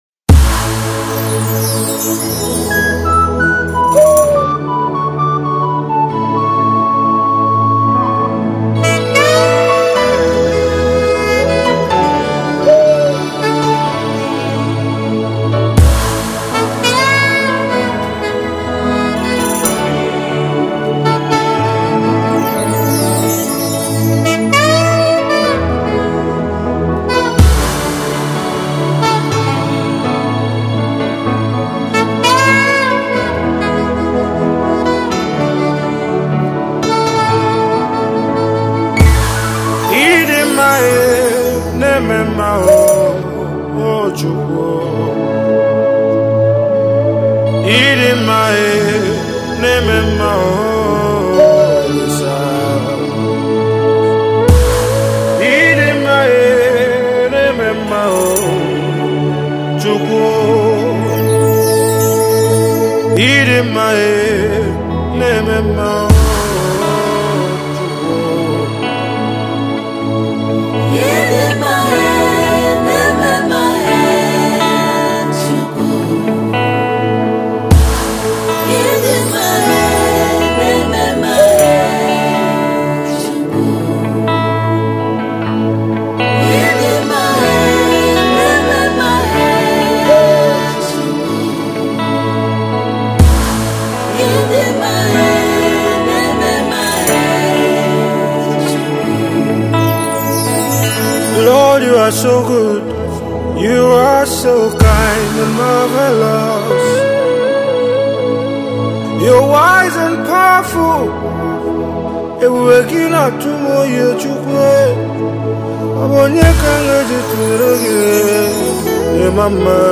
a Nigeria gospel artist
a new worship and holy spirit filled song